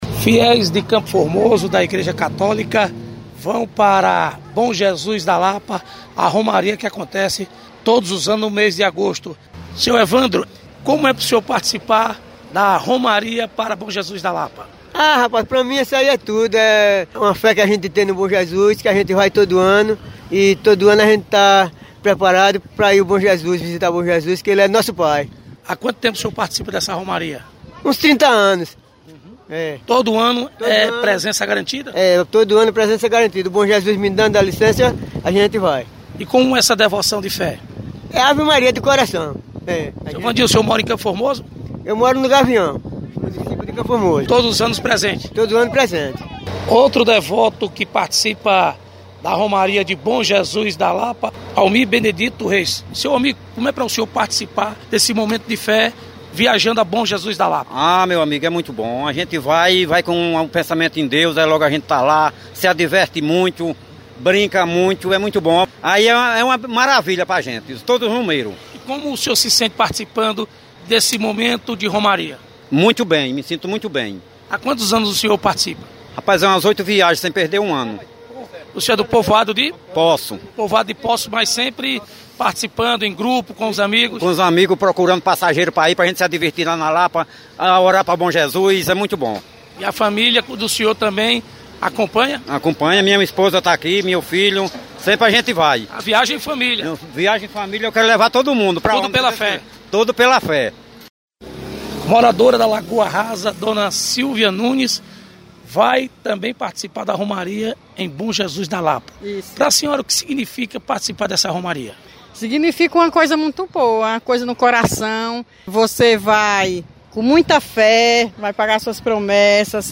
Reportagem com romeiros para Bom Jesus da Lapa